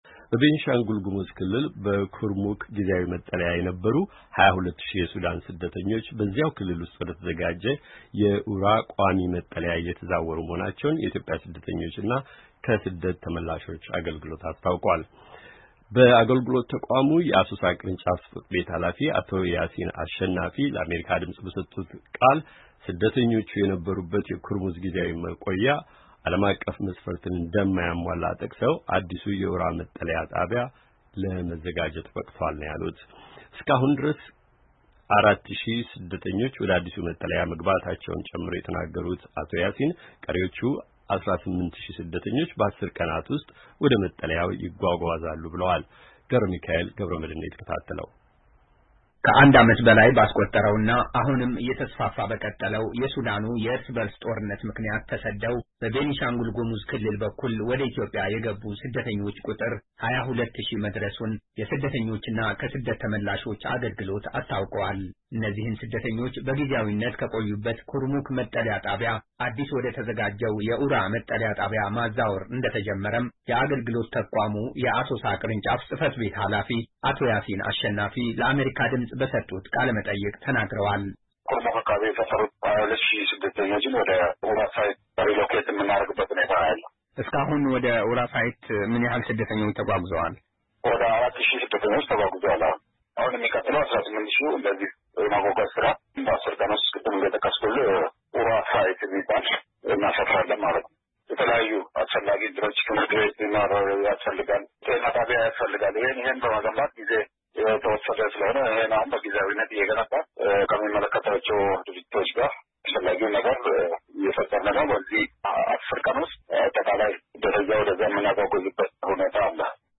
ዜና